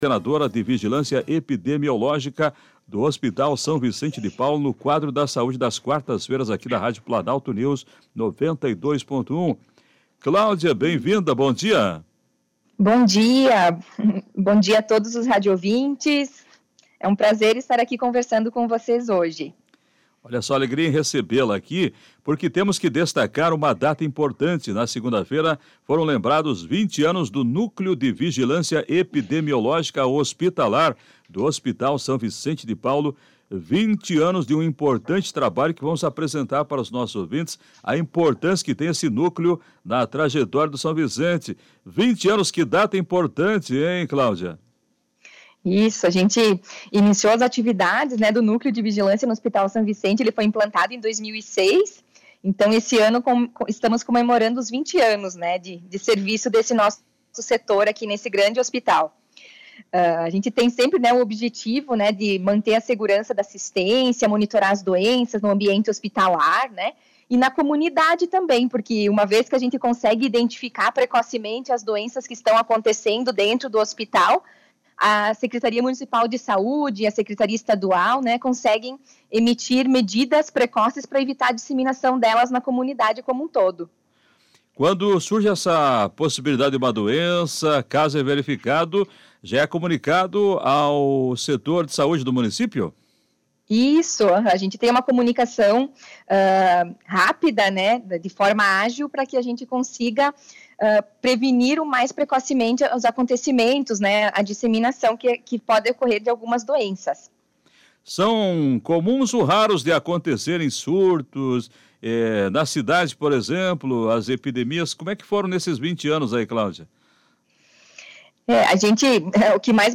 Entrevista: a importância do Núcleo de Vigilância Epidemiológica Hospital do HSVP que chega aos 20 anos
no quadro de saúde do programa Comando Popular, da Rádio Planalto News (92.1).